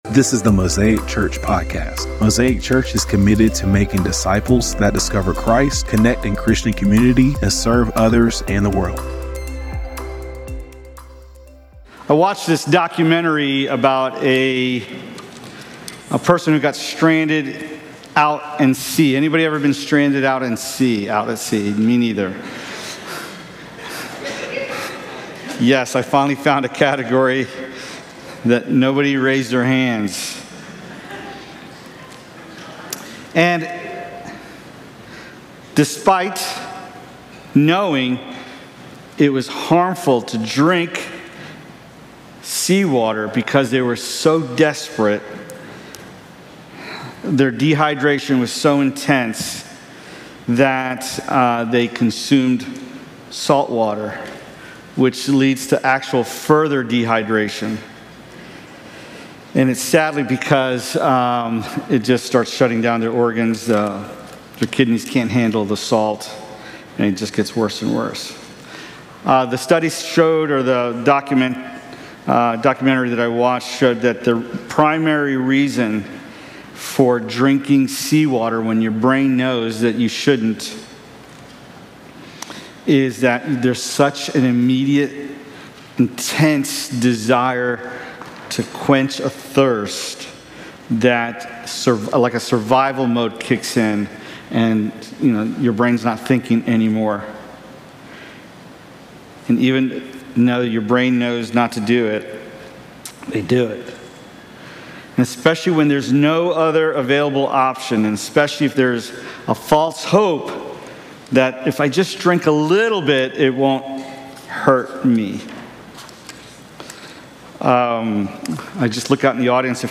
8-10-sermon.mp3